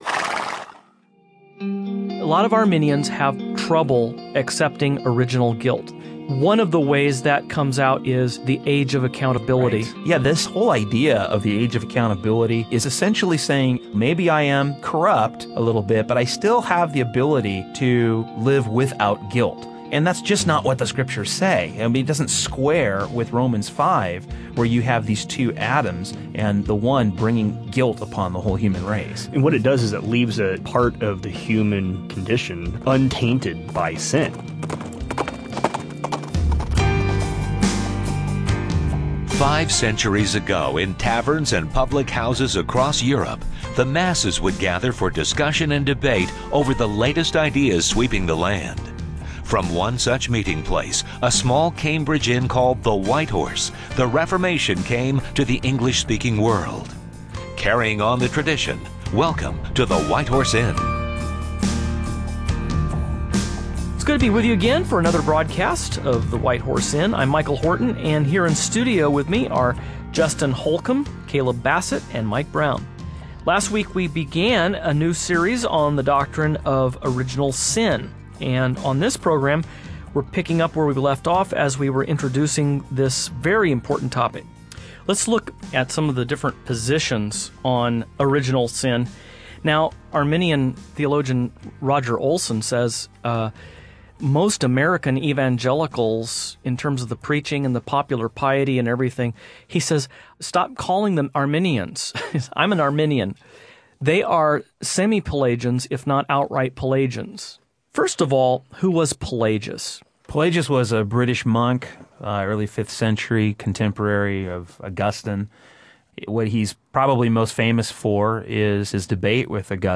Are children born with original guilt and corruption, or does Scripture teach that there is an age of accountability? What did Augustine, Pelagius, or Arminius believe about this important question? On this program the hosts will discuss various perspectives on the effects of the Fall that have arisen throughout the…